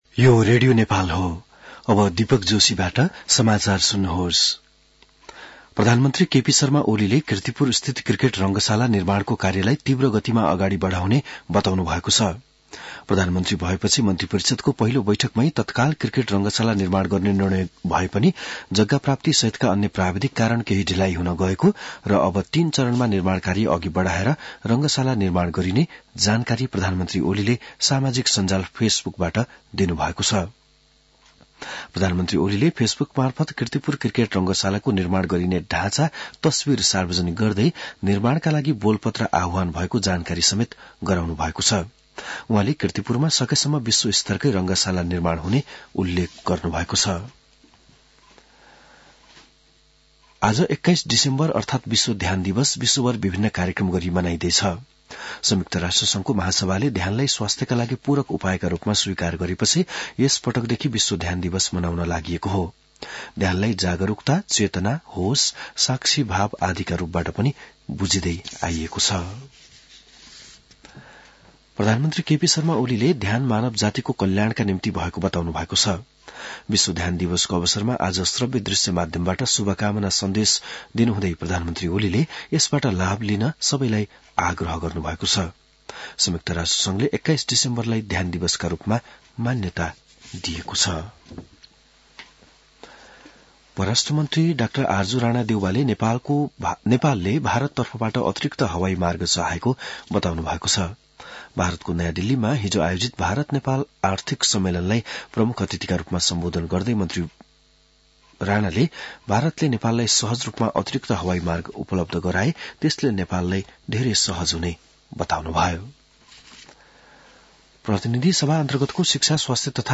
An online outlet of Nepal's national radio broadcaster
बिहान १० बजेको नेपाली समाचार : ७ पुष , २०८१